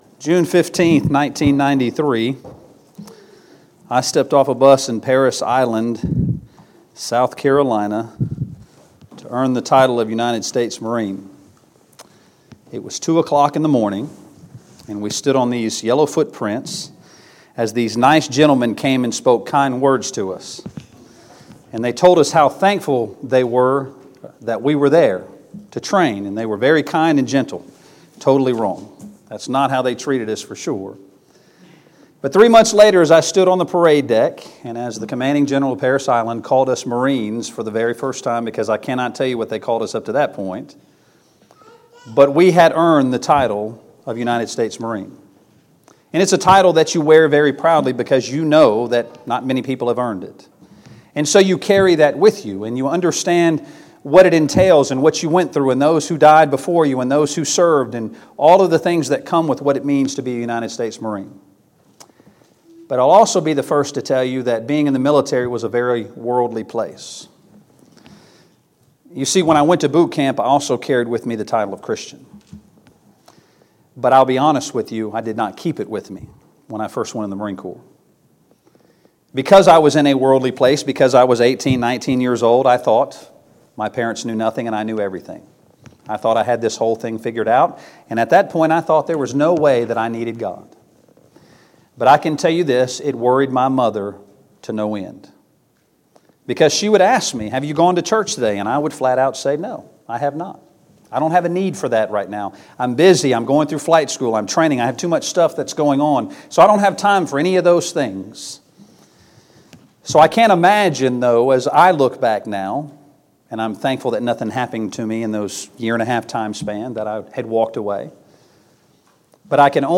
2019 Spring Gospel Meeting Service Type: Gospel Meeting Preacher